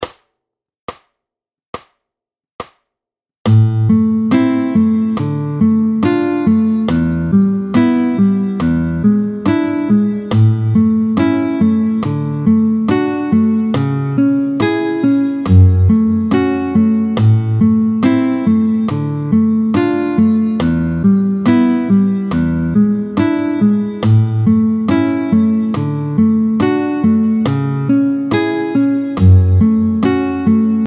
21-06 Modulation Lam (Do) – La majeur, tempo 70
Le morceau débute en LA mineur (DO) avec les accords VIm  IIm  IIIm puis MI7 qui amène par résolution la tonalité de LA majeur avec les accords I  IV  IIIm  VIm…